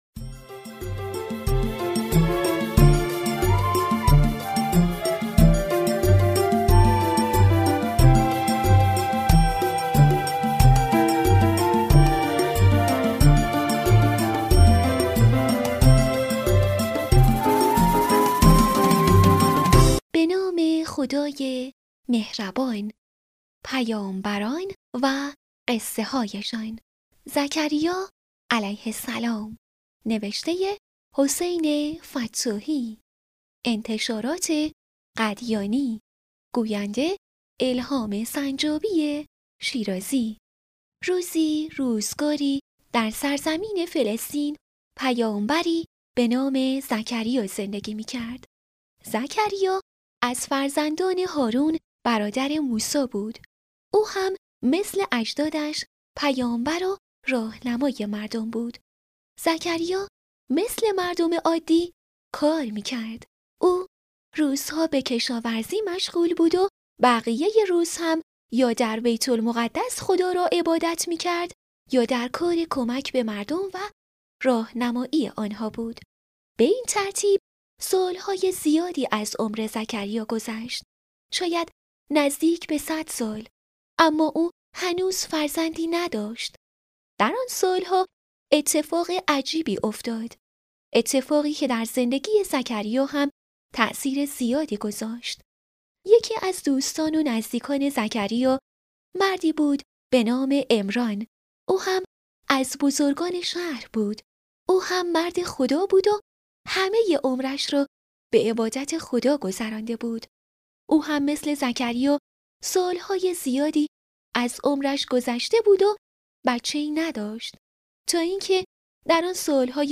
کتاب صوتی «پیامبران و قصه‌هایشان»